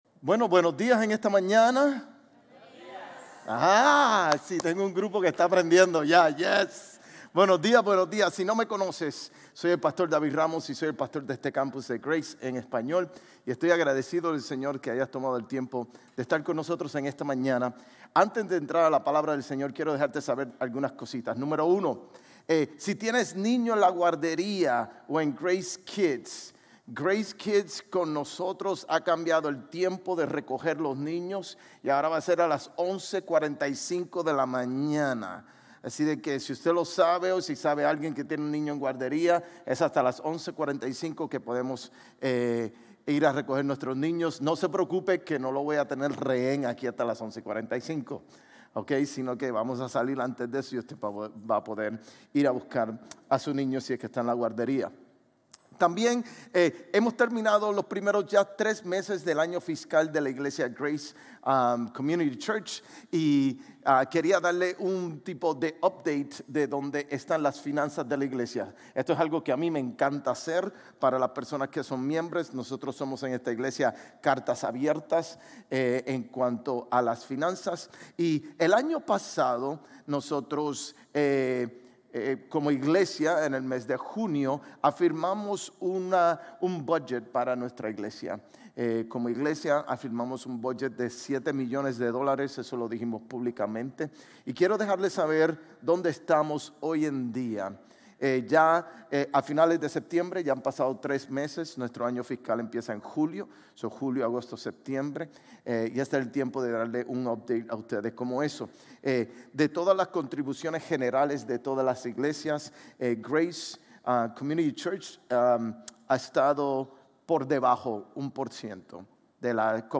GCC-GE-October-22-Sermon.mp3